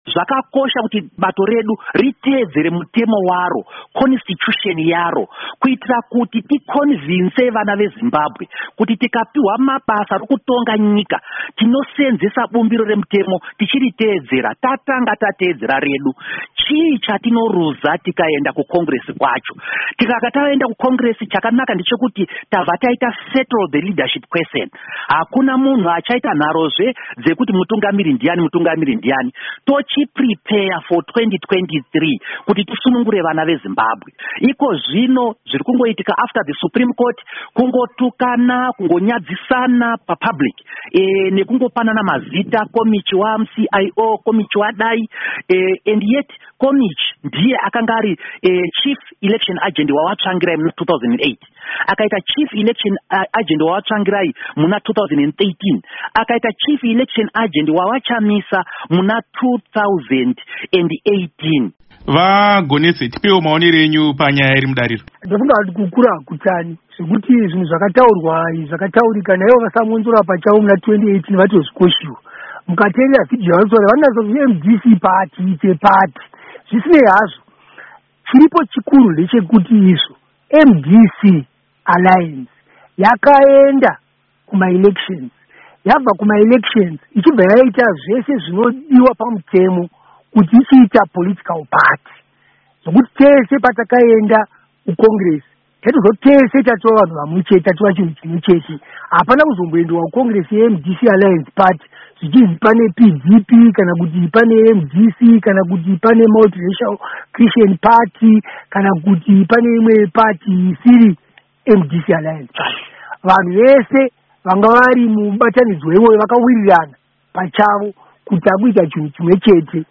Hurukuro naVaInnocent Gonese naVaDouglas Mwonzora